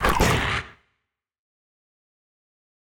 guardian_hit3.ogg